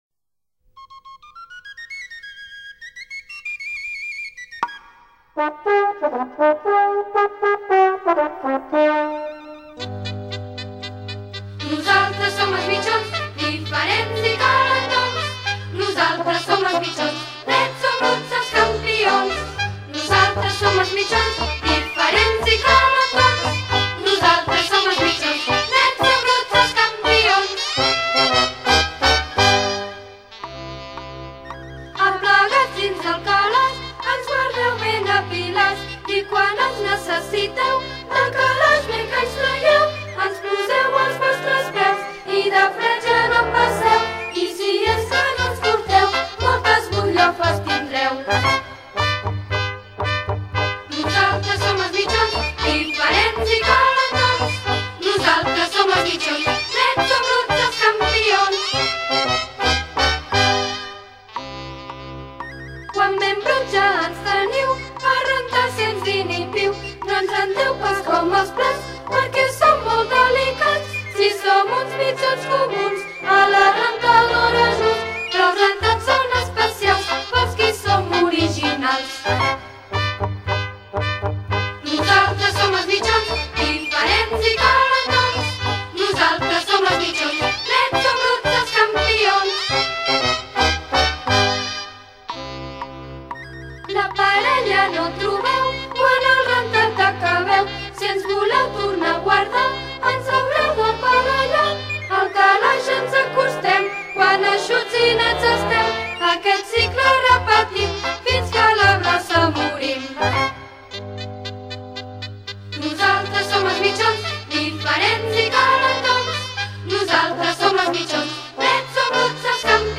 A la classe de 3r estem aprenent la cançó dels mitjons, una sardana molt divertida:
Cancó: